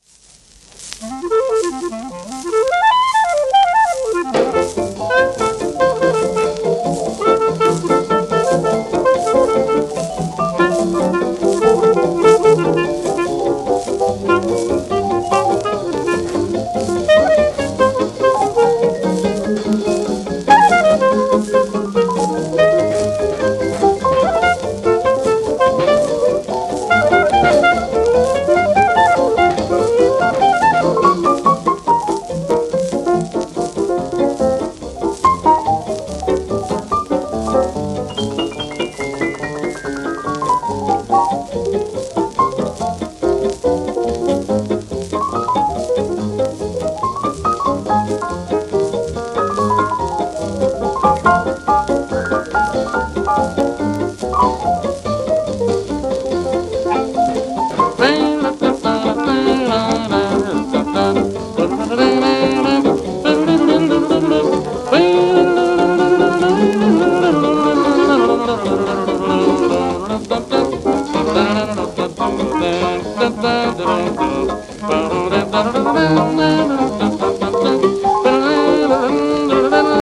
w/セクステット
シェルマン アートワークスのSPレコード